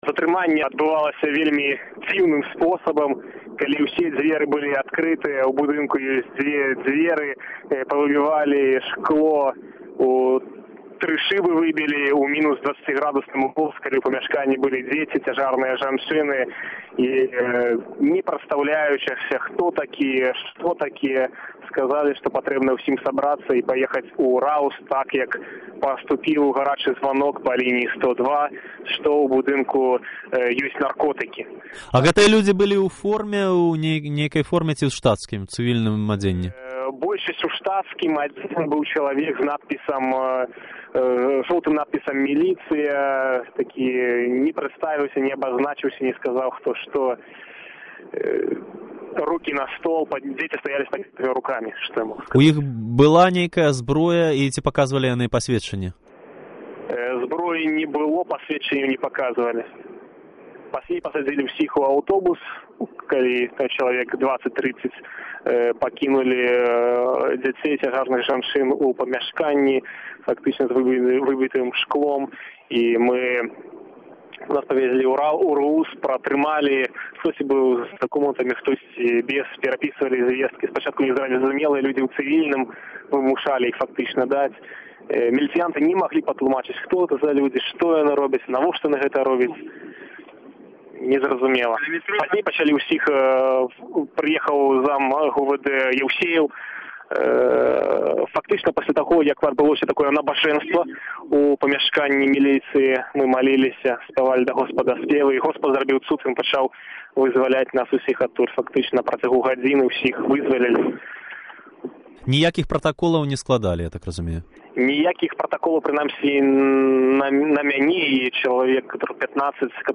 Гутарка з затрыманым сябрам "Ліцьвінскага клюбу"